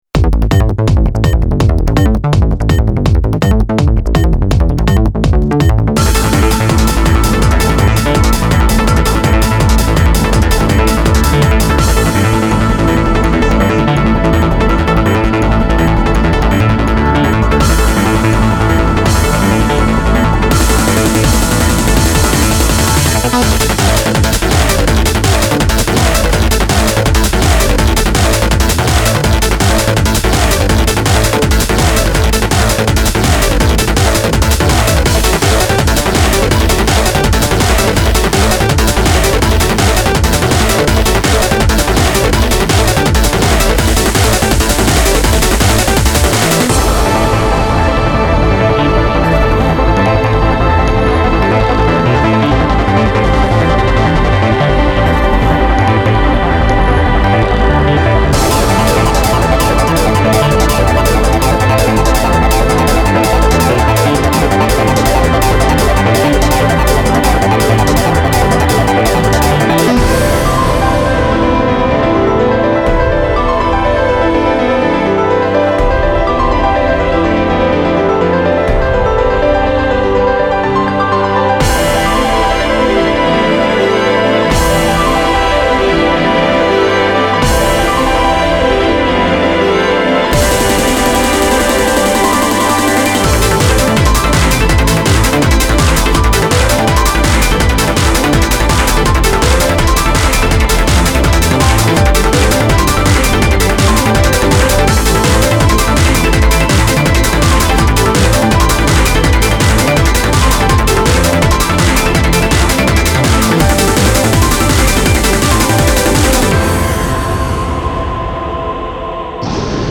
BPM41-165